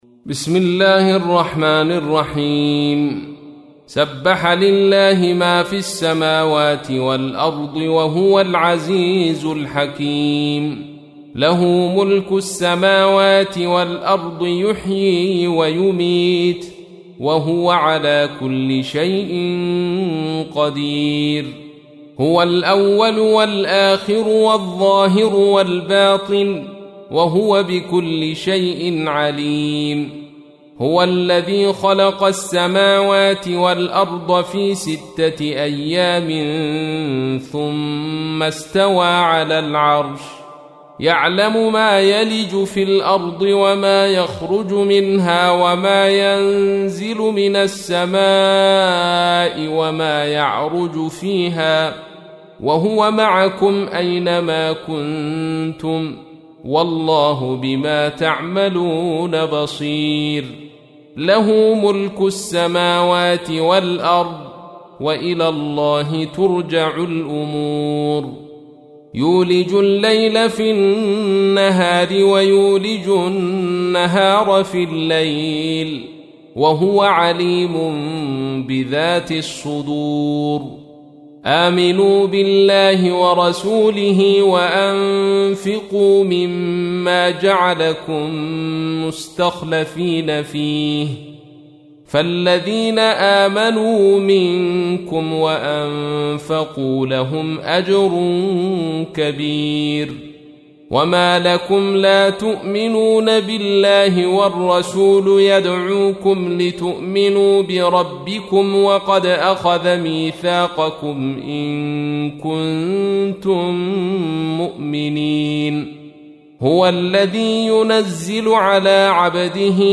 تحميل : 57. سورة الحديد / القارئ عبد الرشيد صوفي / القرآن الكريم / موقع يا حسين